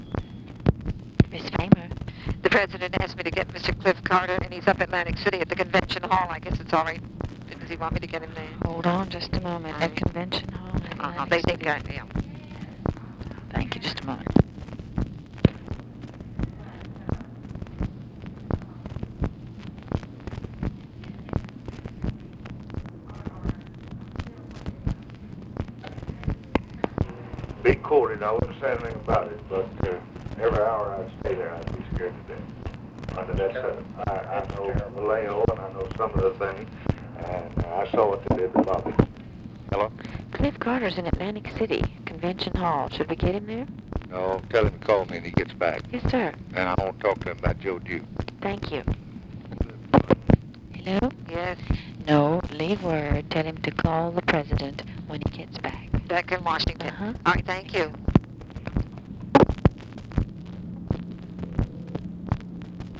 Conversation with TELEPHONE OPERATOR
OFFICE CONVERSATION